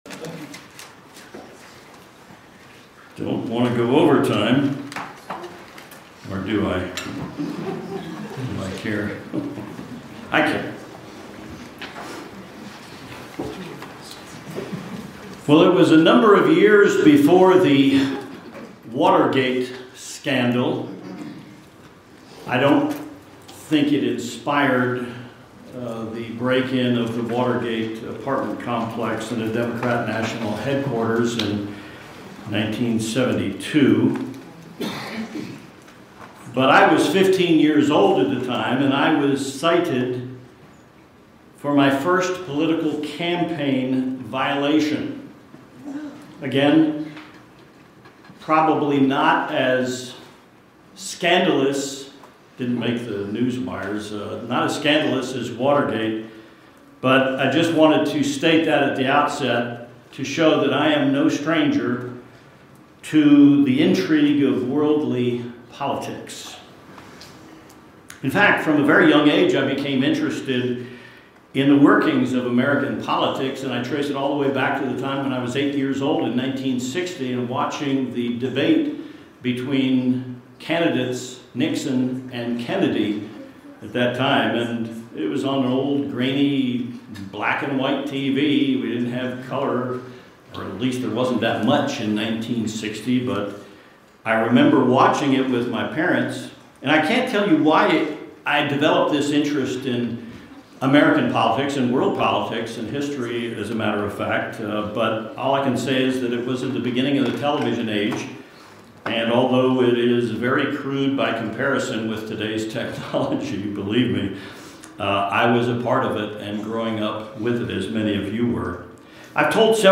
It's important that we keep a balance as Christians in the world of politics. This sermon shows you how.